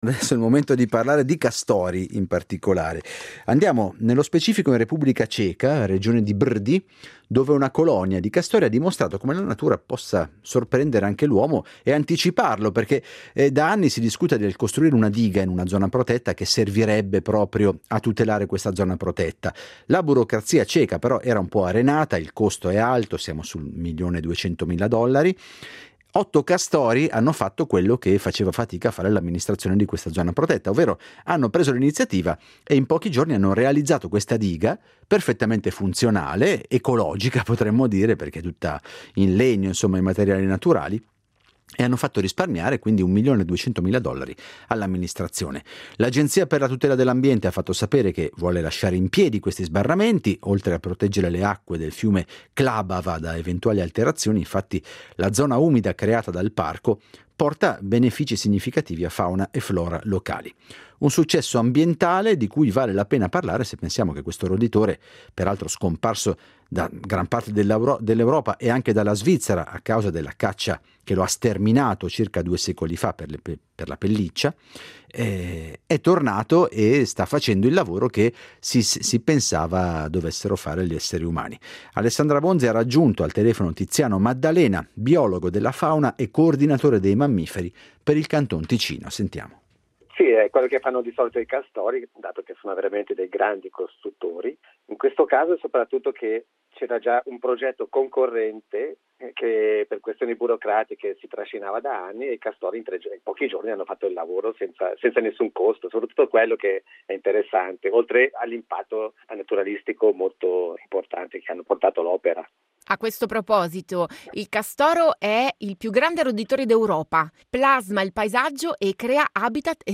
ha raggiunto al telefono